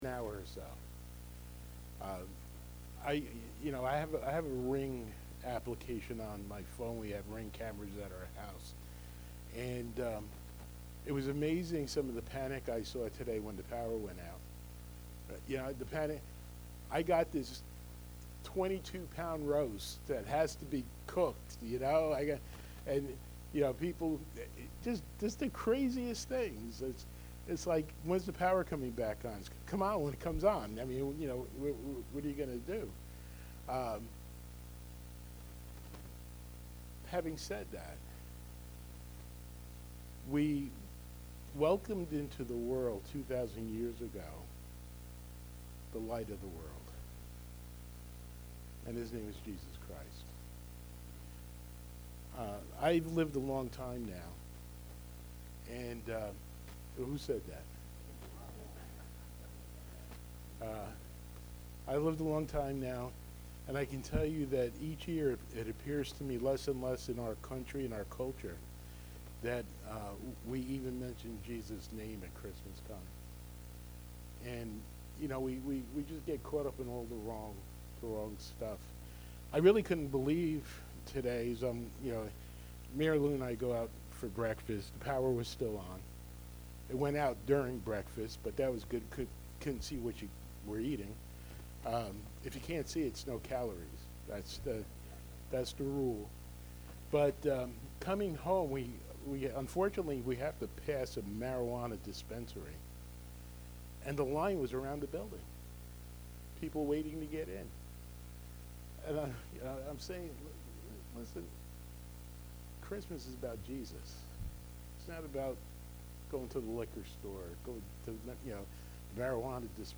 Enjoy our Christmas Eve. Candlelight Service/